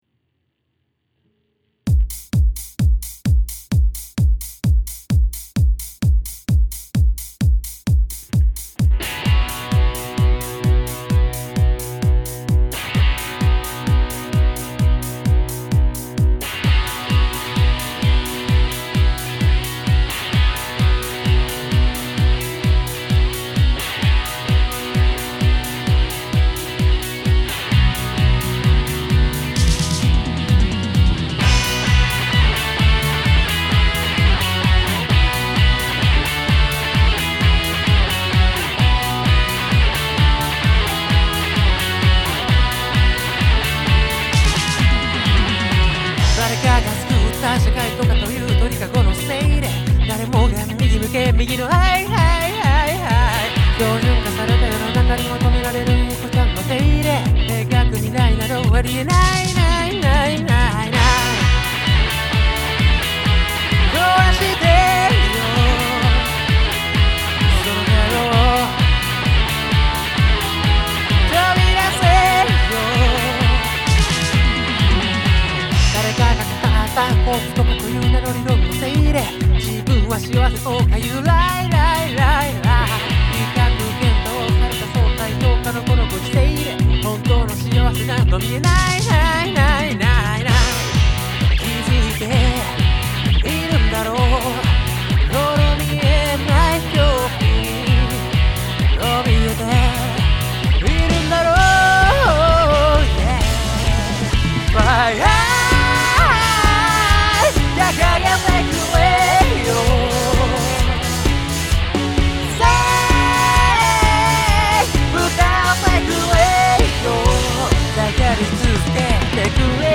ただ、ただ、自分らしくあろうとするパッションを描いたロックチューン